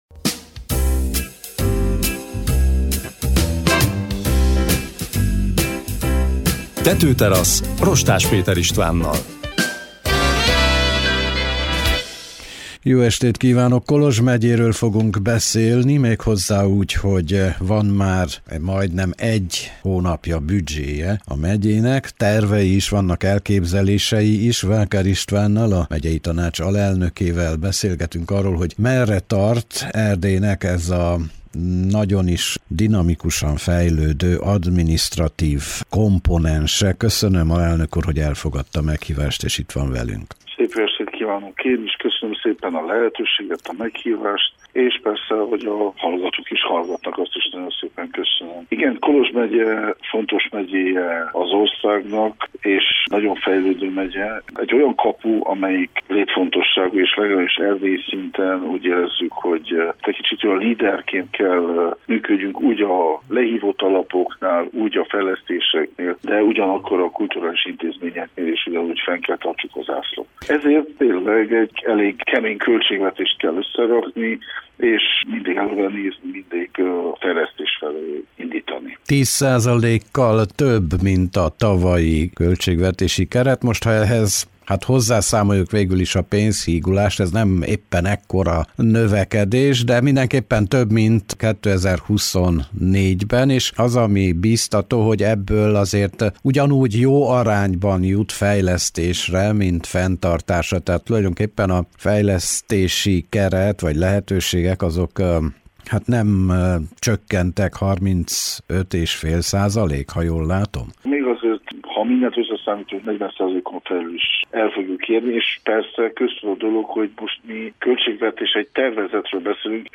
Kis örményországi intermezzó is része a Vákár Istvánnal készült beszélgetésnek. A Kolozs megyei Tanács alelnöke a Tetőterasz vendége volt.